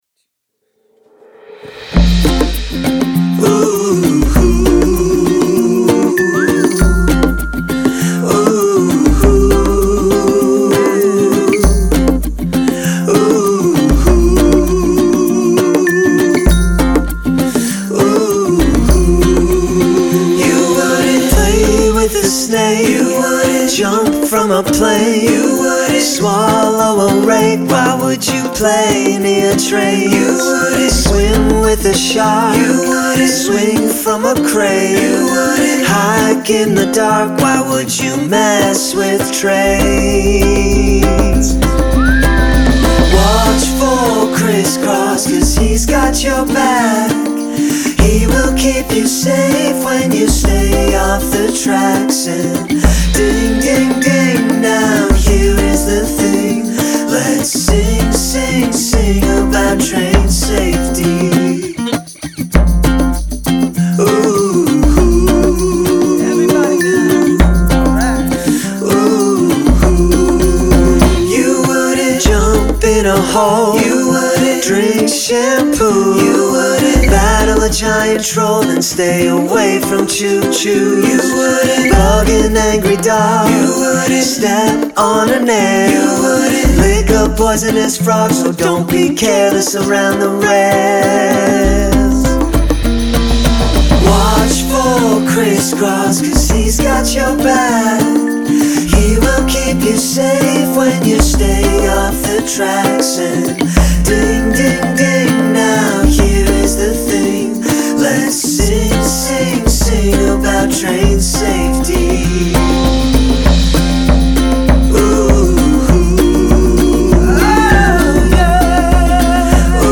Song MP3